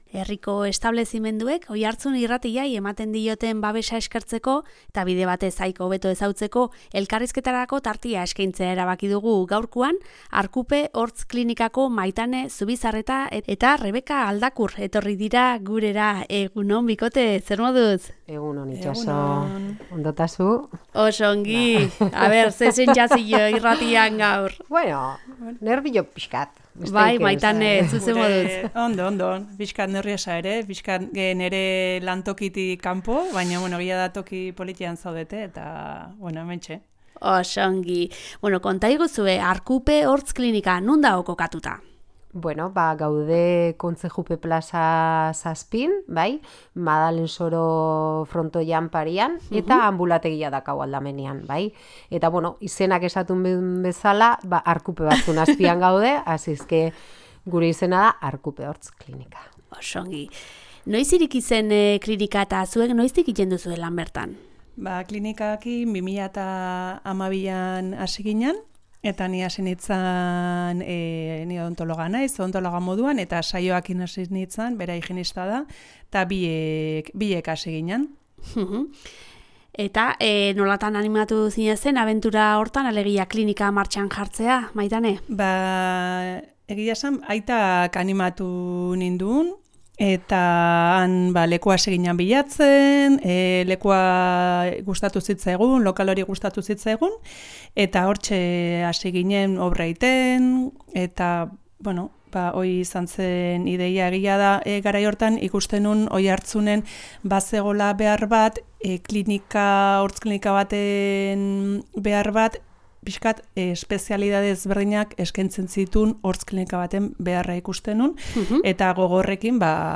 Herriko establezimenduek Oiartzun Irratiari ematen dioten babesa eskertzeko eta bide batez haiek hobeto ezagutzeko, elkarrizketarako tartea eskaintzea erabaki dugu.